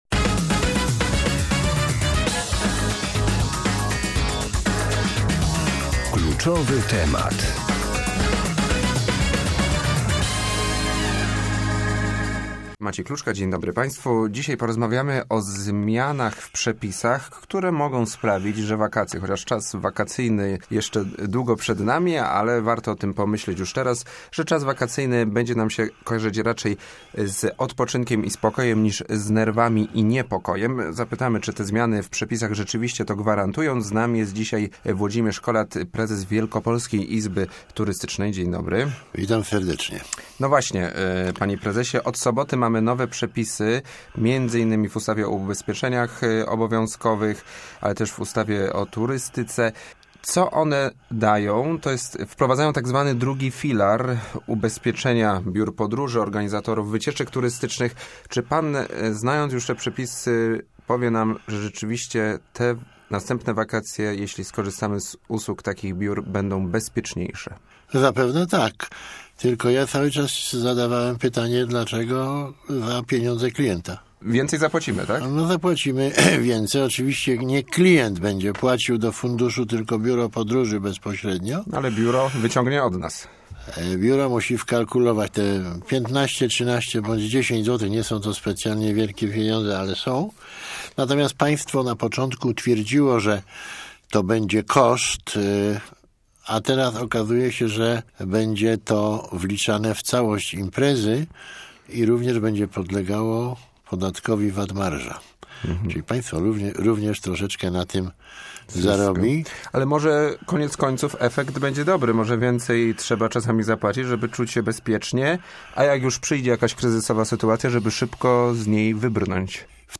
O wakacjach w listopadzie rozmawialiśmy w porannej rozmowie Radia Merkury.